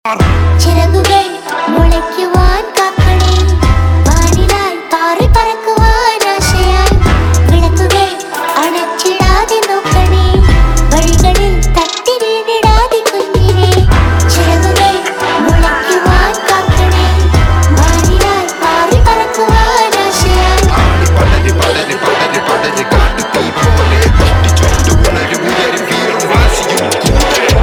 Malayalam Film Song, Soft Rock, Ballad •Film